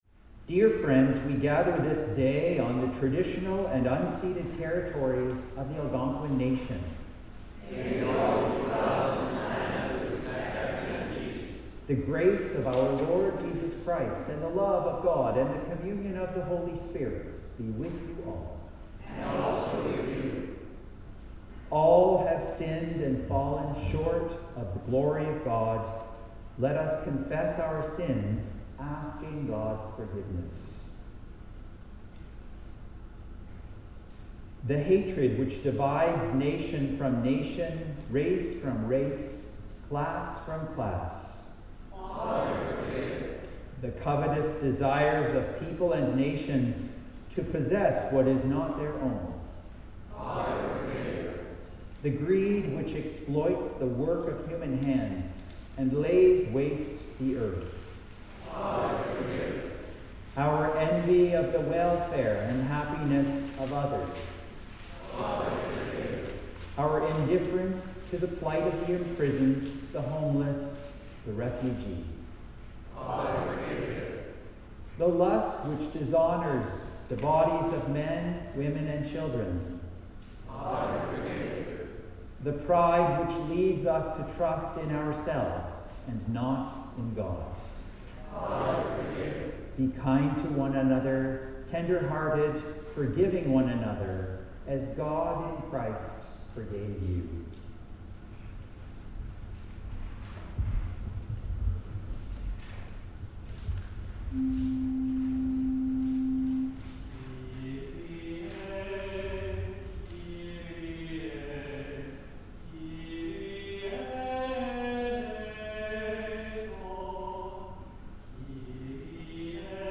Sermons | St John the Evangelist
Second Reading: Romans 10:8b-13 (reading in French)
The Lord’s Prayer (sung)